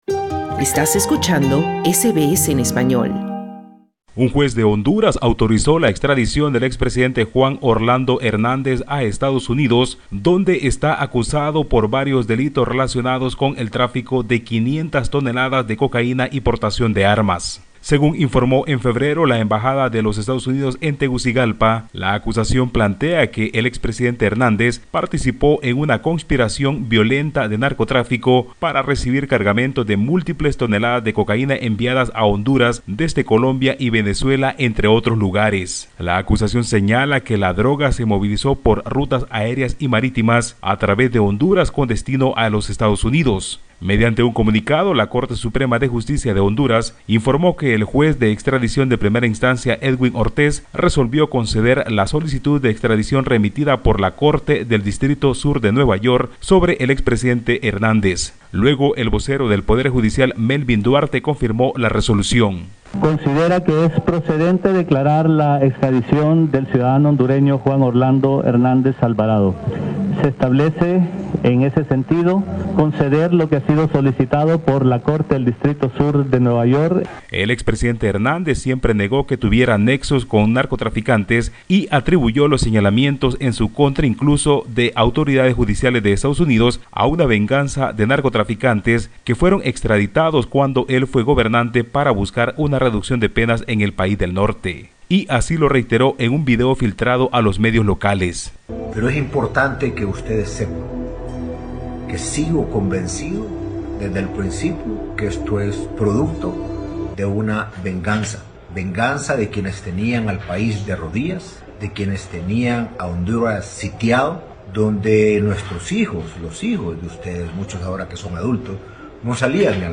Un juez hondureño autorizó la extradición del expresidente Juan Orlando Hernández a Estados Unidos, donde estaría enfrentando tres cargos asociados a narcotráfico y uso de armas, pero el exmandatario reiteró que se trata de una venganza de narcotraficantes. Escucha el informe del corresponsal de SBS Spanish en Latinoamérica